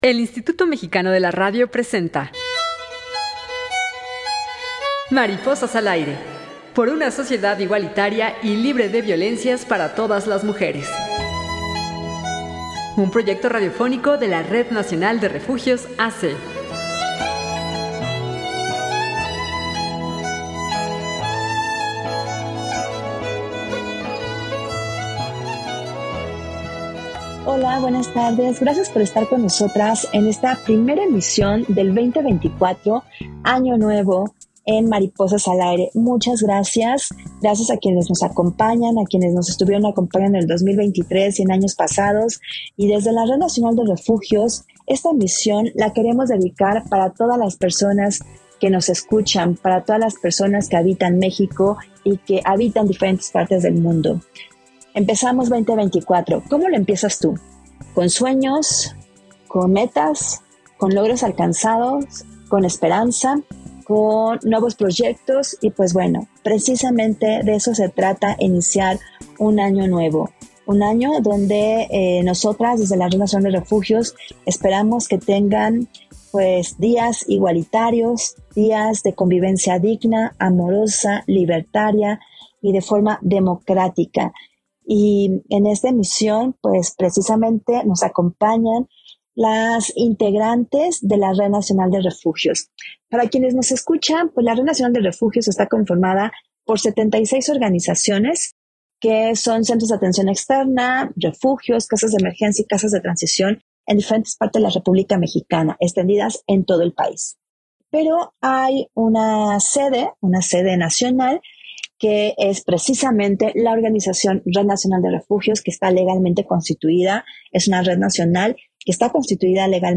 Enlaces Territoriales para la Equidad de Escuchamos en este programa casi todas las voces de las integrantes de la Red Nacional de Refugios A C, Sus deseos para 2024 y para todas las mujeres que estén libres de violencia y sean felices.